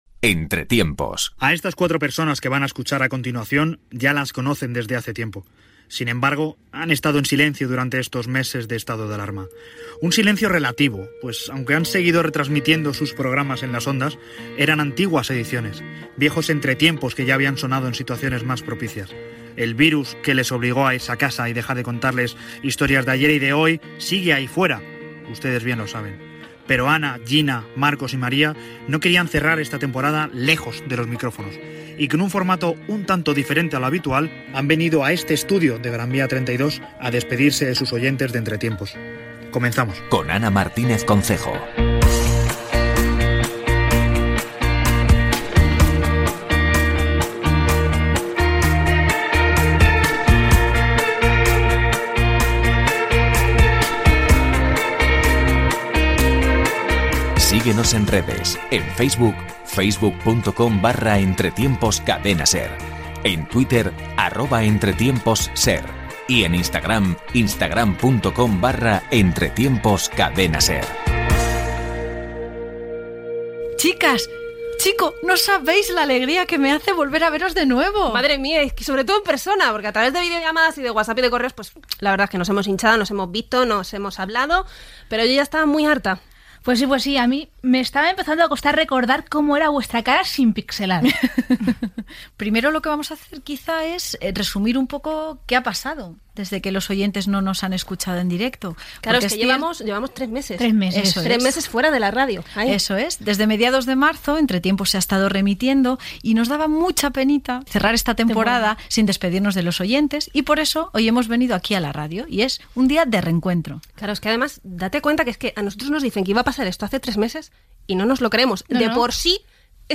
Conversa telefònica
Divulgació